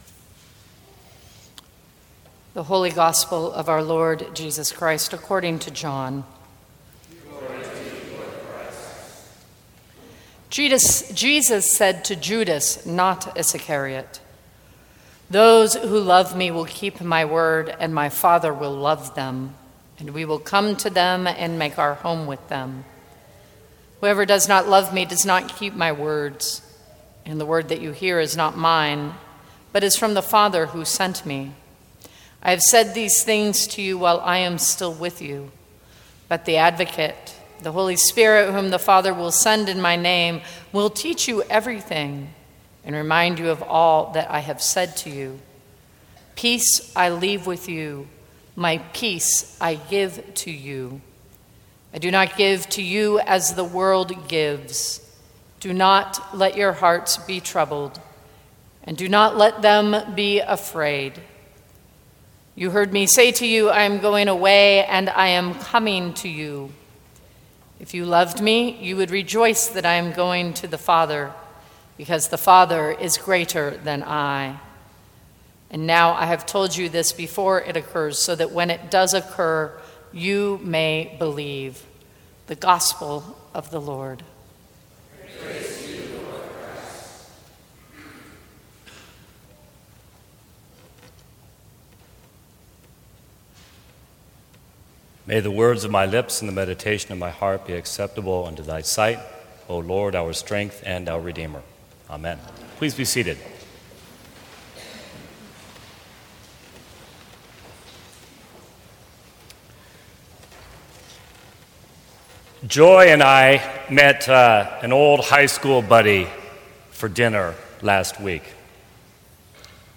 Sermons from St. Cross Episcopal Church Sixth Sunday in Easter May 02 2016 | 00:13:48 1x 00:00 / 00:13:48 Subscribe Share Apple Podcasts Spotify Overcast RSS Feed Share Link Embed